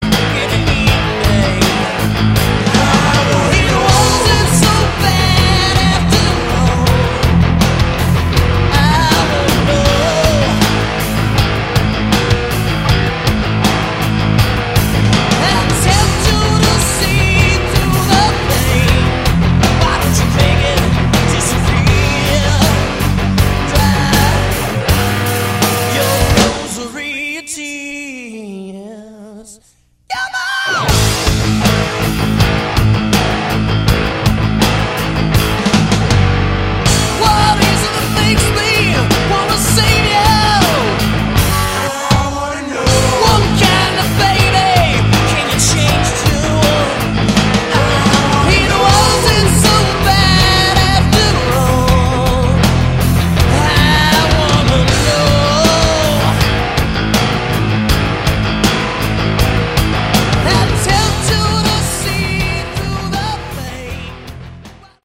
Category: Hard Rock
lead vocals
guitar
bass
drums